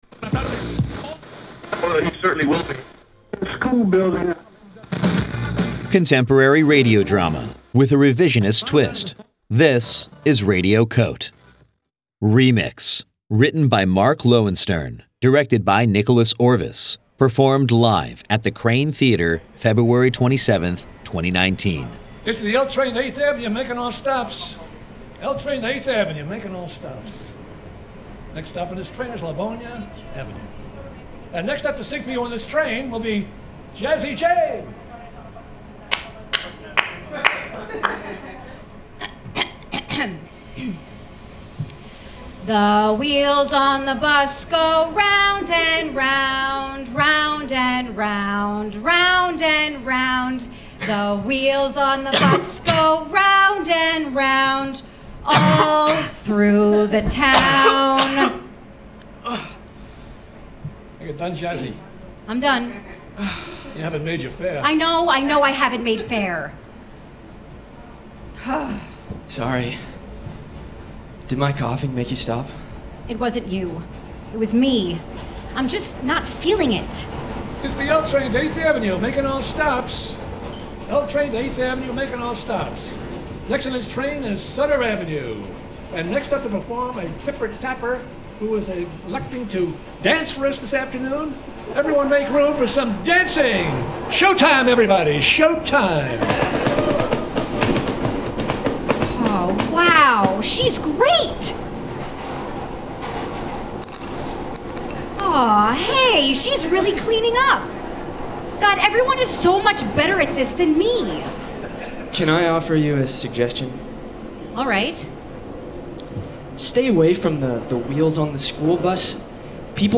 performed live at Radio COTE: 24-hour Newsroom in the 2019 FRIGID Festival at The Kraine Theater, New York City, February 27, 2019